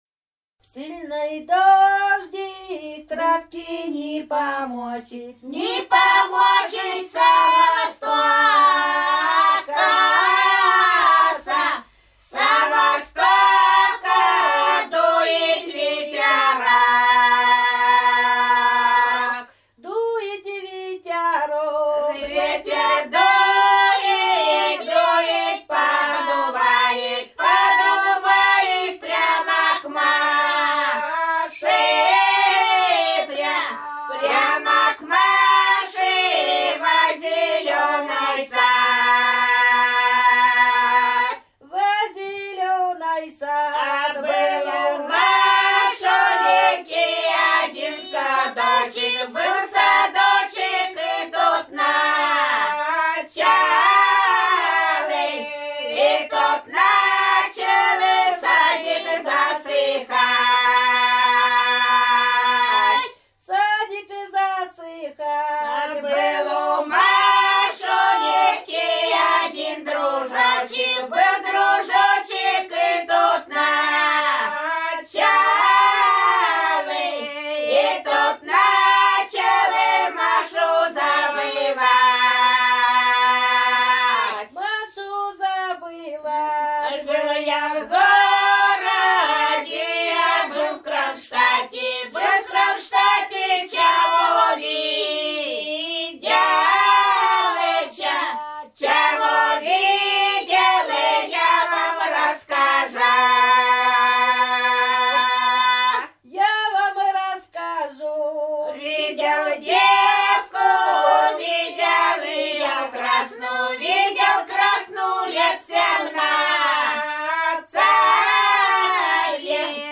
Рязань Кутуково «Сильный дождик», лирическая.